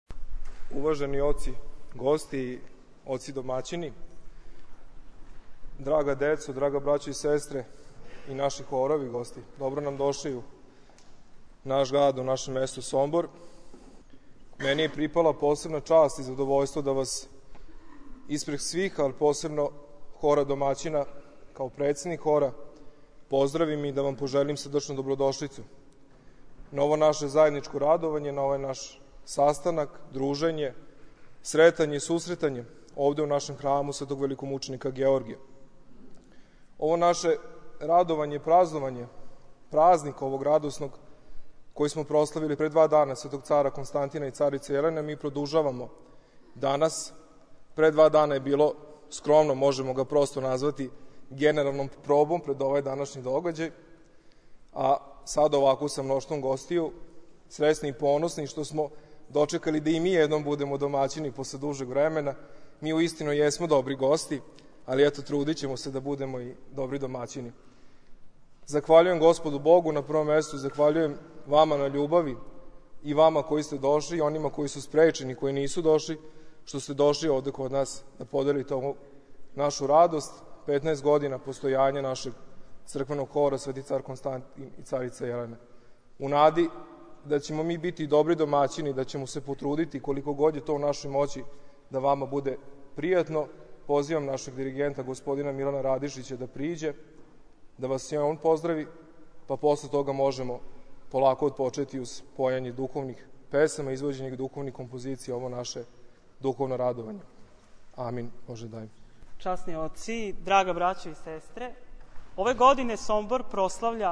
У Сомбору одржана смотра хорова Епархије бачке
pozdravni govor.mp3